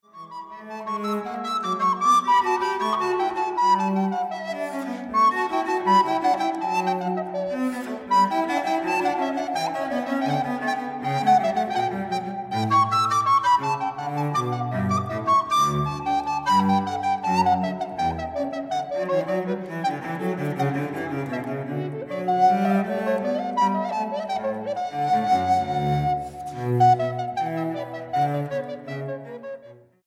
flautas